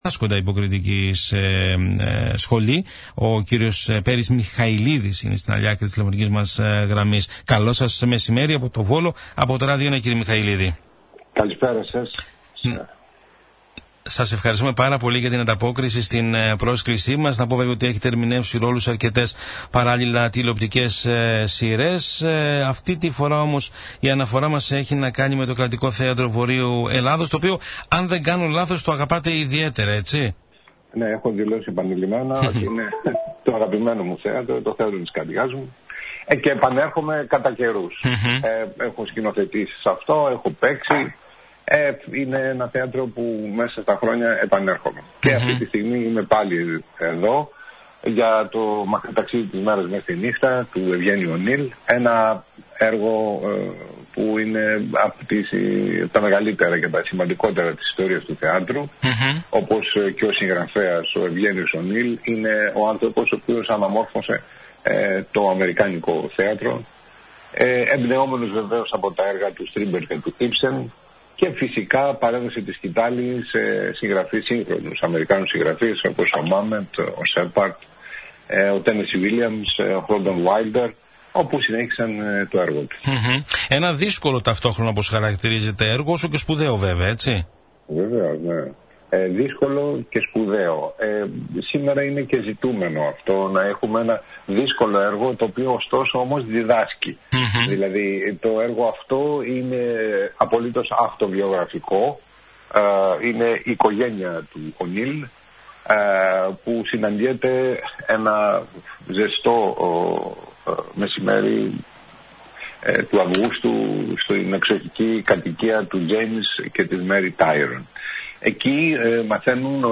Ακούστε την συνέντευξη Ο δημοφιλής ηθοποιός, επανέλαβε την αγαστή συνεργασία με το ΚΘΒΕ και την αγαπητική σχέση που τον φέρνει πάντα πίσω στη Θεσσαλονίκη.